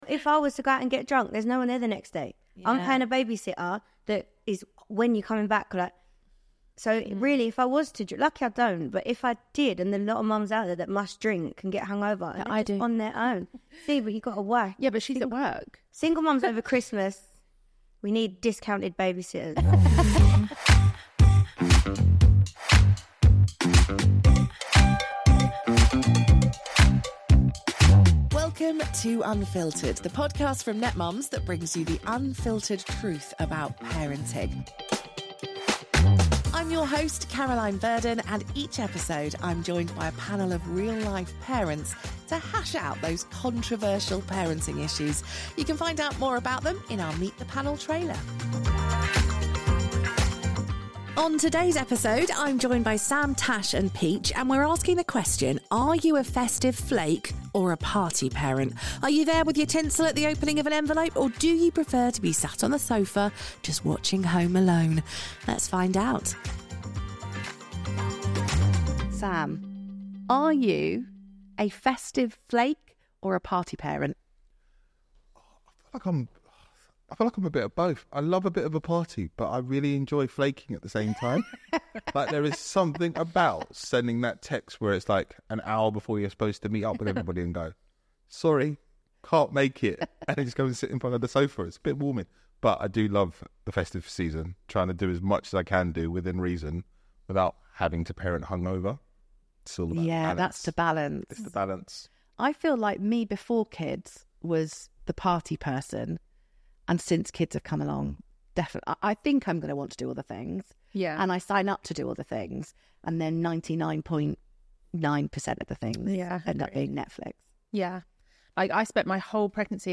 our lively panel of parents as they chat about the ups and downs of parenting during the festive season.